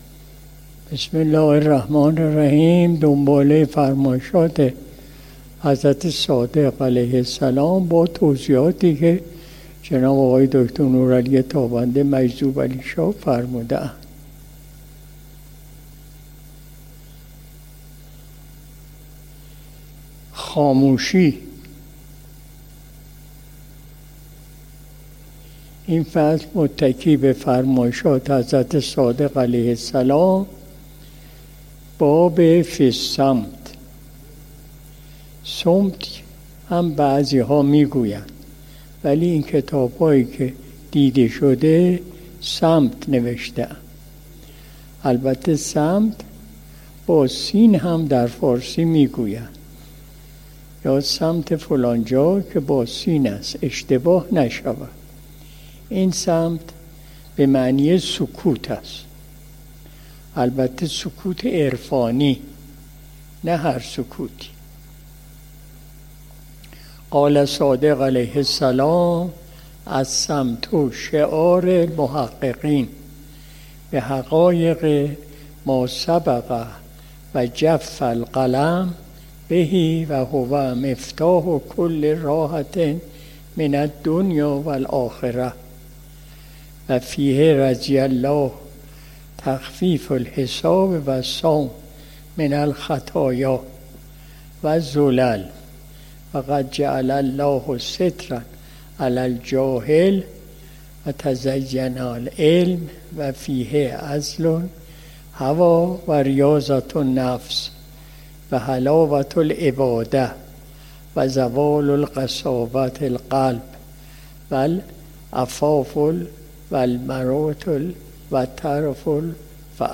مجلس شب دوشنبه ۱۵ مرداد ماه ۱۴۰۲ شمسی